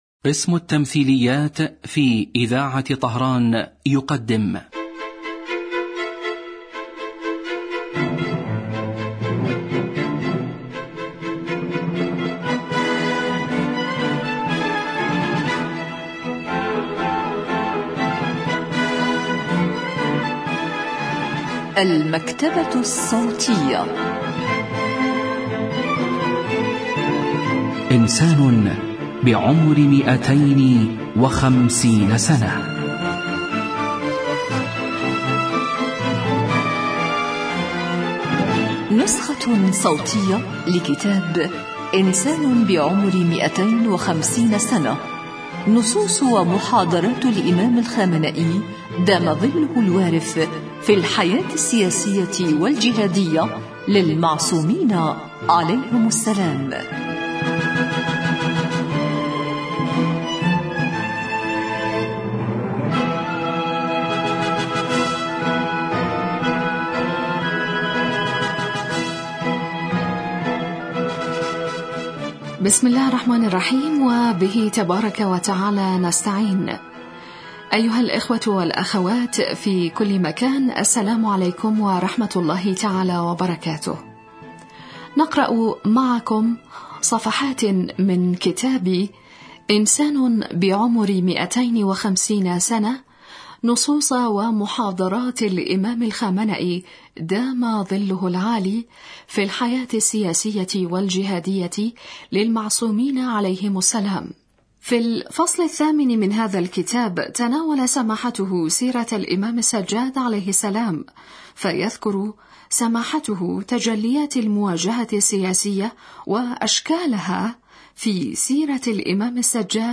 إذاعة طهران- إنسان بعمر 250 سنة: نسخة صوتية لكتاب إنسان بعمر 250 سنة للسيد علي الخامنئي في الحياة السياسية والجهادية للمعصومين عليهم السلام.